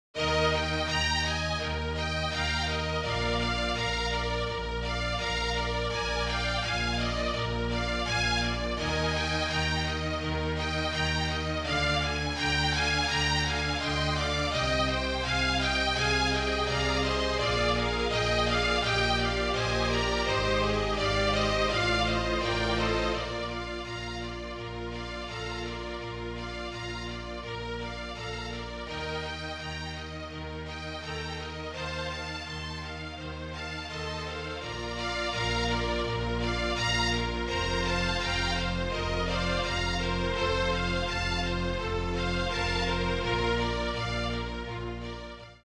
MIDI
A. for flute or solo violin and strings